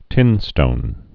(tĭnstōn)